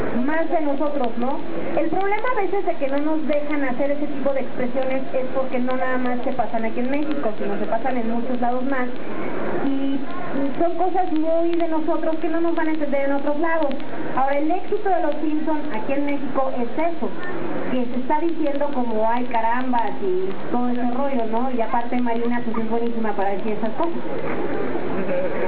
En la convención Roca Poca de marzo de 1997 se realizó una conferencia de doblaje que duró aproximadamente una hora.